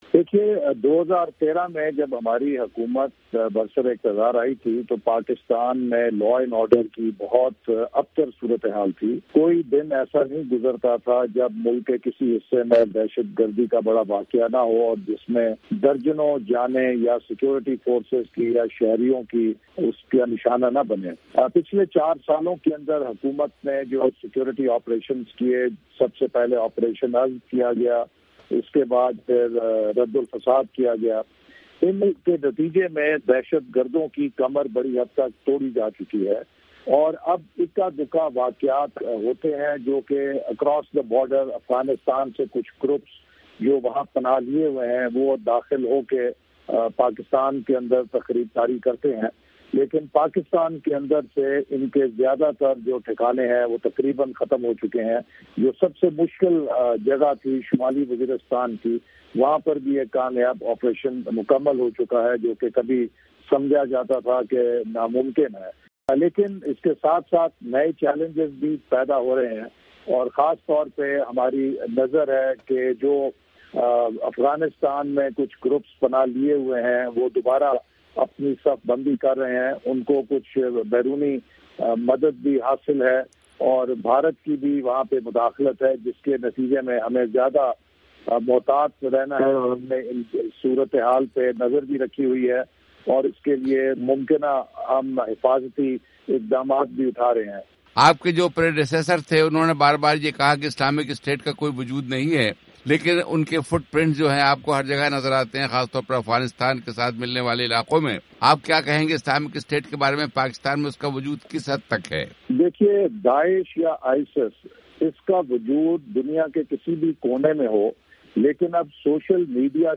انٹرویو